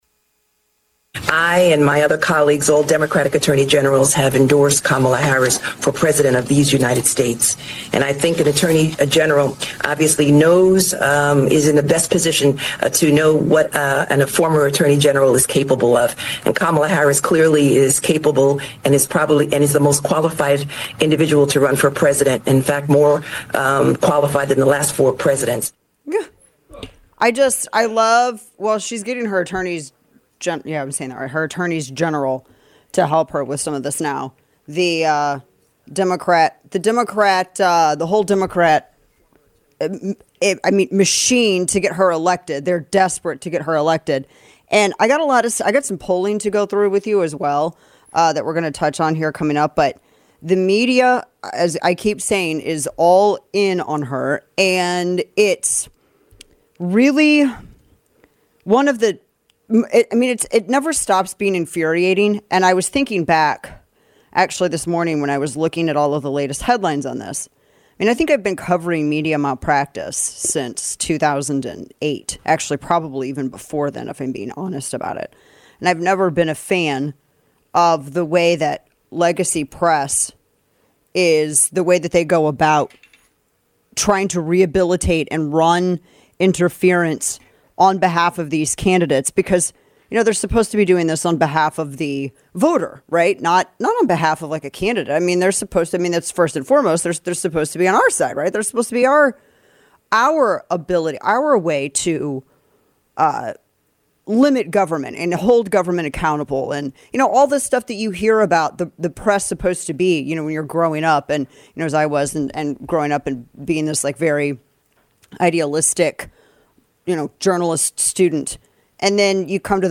Texas Governor Greg Abbott joins us to explain Operation Lonestar, Kamala Harris' dangerous border policies and much more.